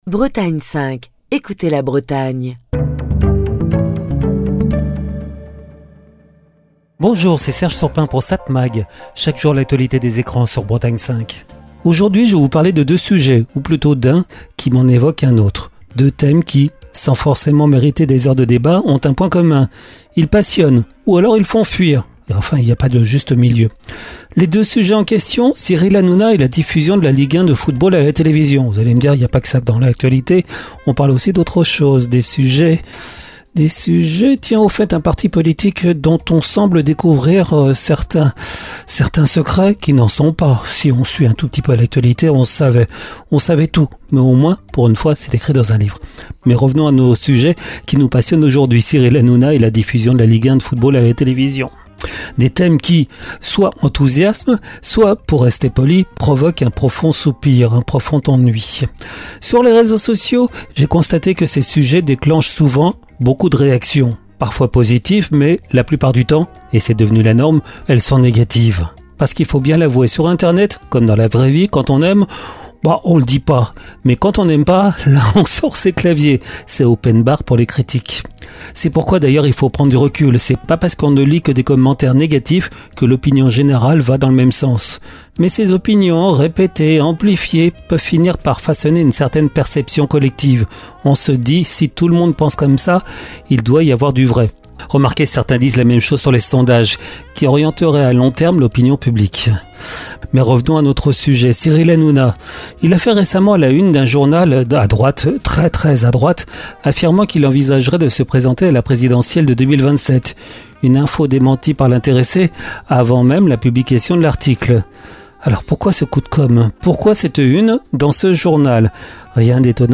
Chronique du 8 mai 2025.